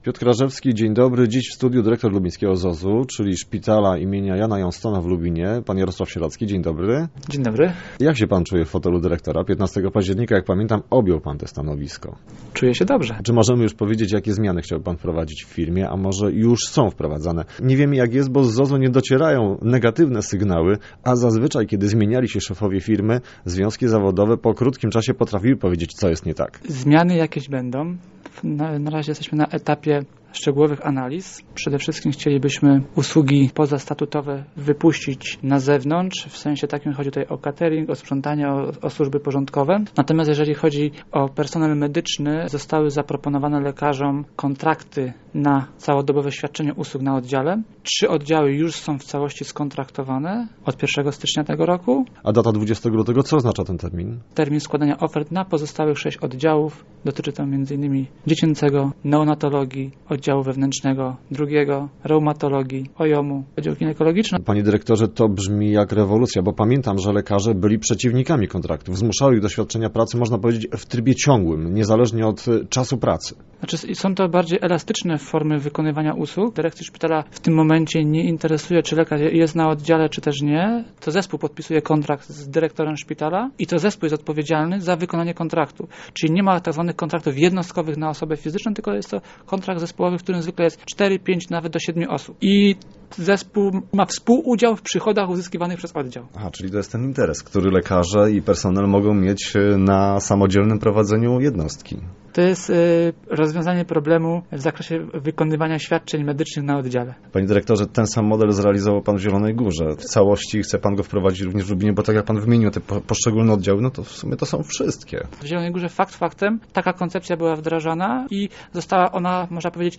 Jak przekonał lekarzy do zmiany sposobu zatrudnienia? O tym, a także o inwestycjach i dalszych planach rozmawialiśmy dziś na antenie Radia Elka.